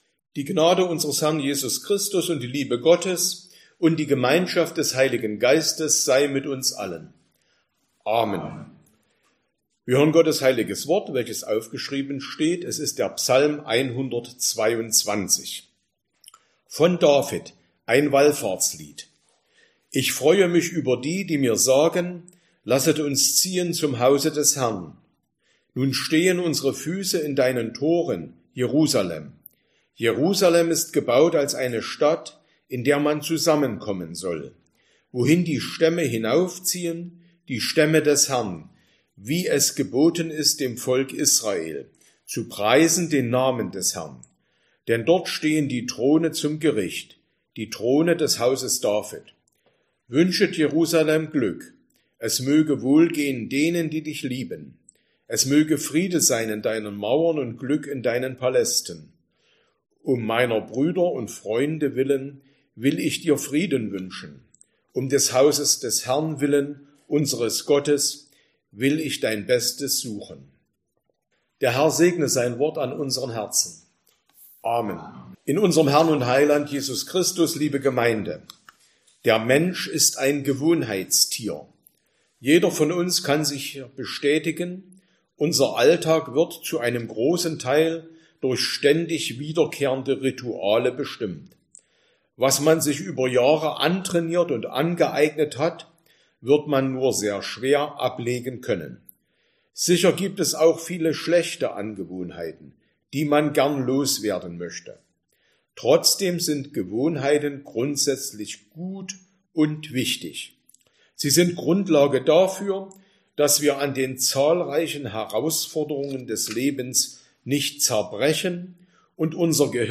Sonntag nach Trinitatis Passage: Psalm 122 Verkündigungsart: Predigt « 16.